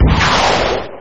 rocket-turret-fire.ogg